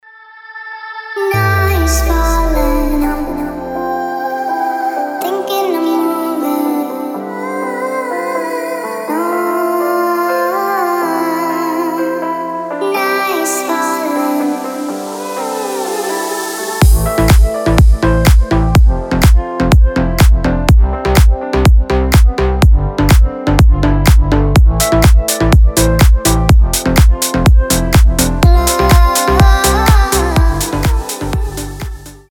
• Качество: 320, Stereo
красивые
deep house
мелодичные
нарастающие
приятные
детский голос
Приятный будильник с детским голоском